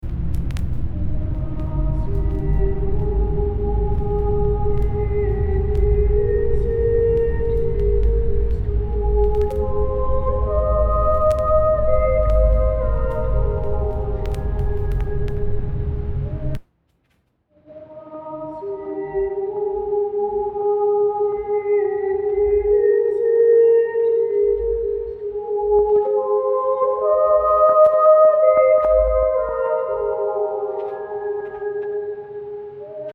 The first clip is a recording of Andrew Carnegie from 1914. It has a lot of background noise for several reasons—the main one being that the recording is 100 years old.